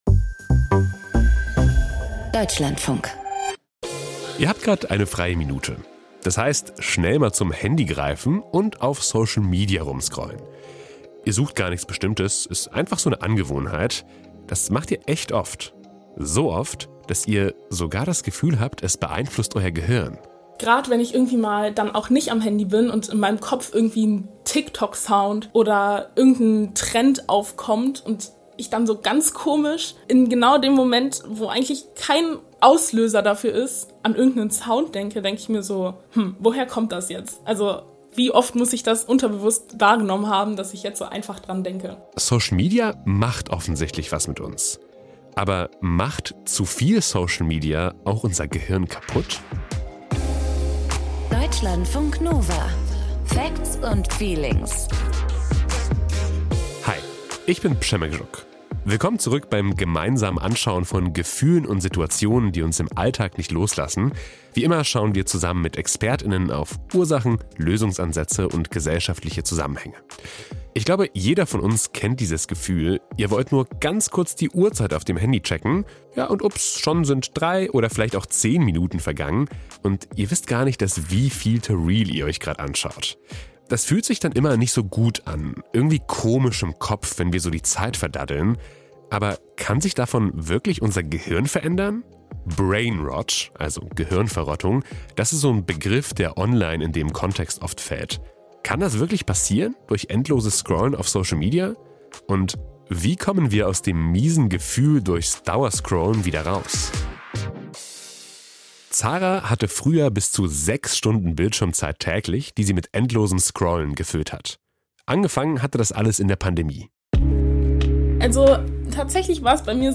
Die Reizüberflutung war zu viel. Welchen Einfluss endless Scrolling auf unser Gehirn hat und ob es das sogar verändern kann, erklären zwei Experten.